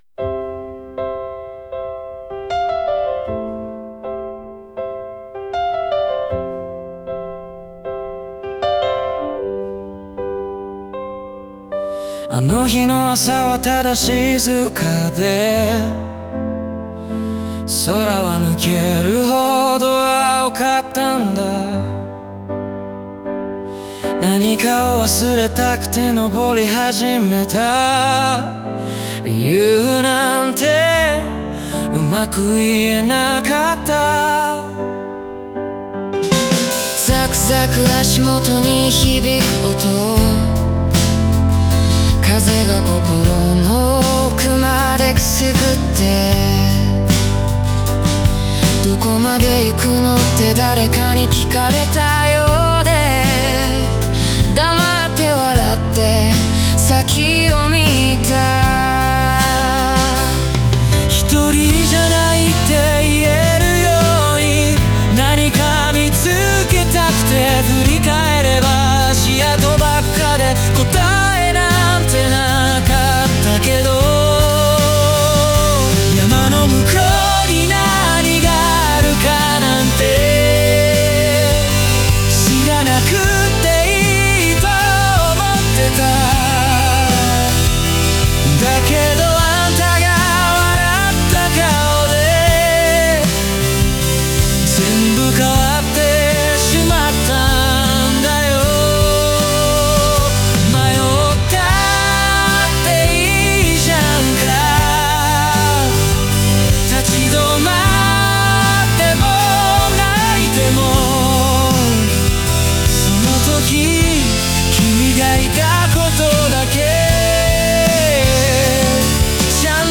オリジナル曲♪
二人の声が交わる部分では、孤独から支え合いへと変わる心の変化が繊細に表現されていて、聴き手に温かさを届けます。